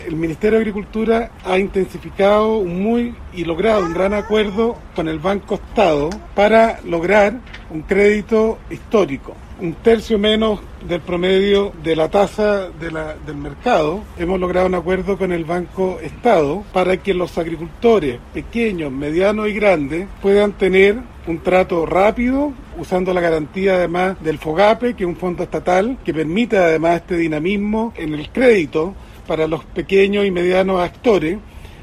El Ministro de Agricultura, Esteban Valenzuela detalló cómo se generó esta alianza.